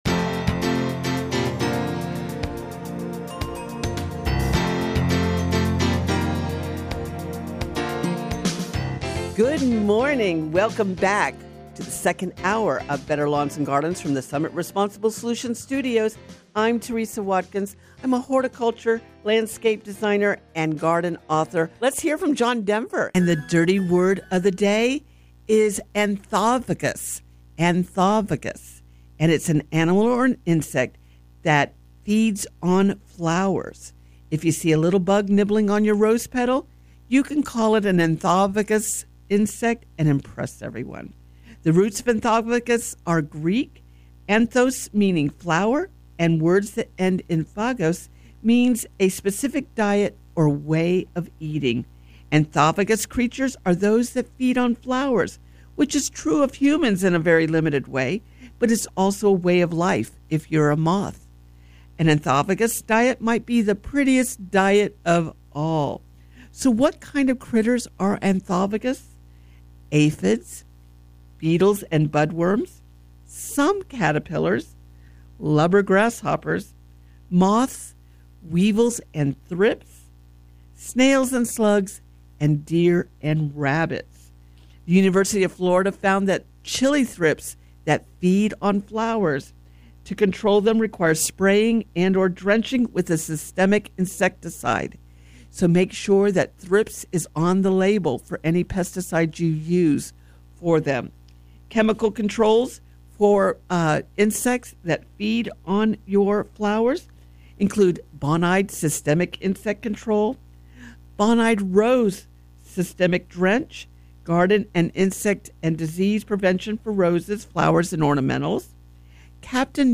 Florida’s most popular garden radio show